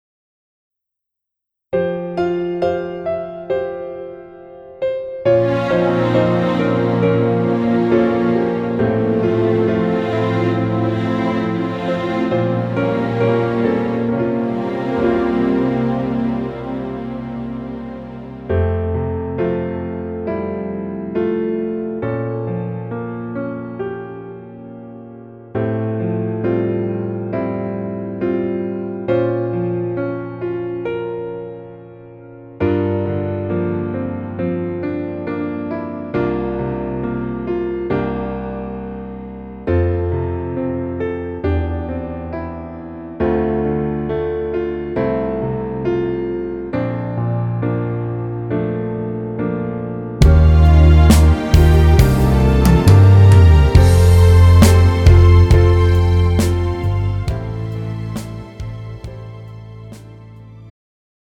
장르 축가 구분 Pro MR
가사   (1절 앞소절 -중간삭제- 2절 후렴연결 편집)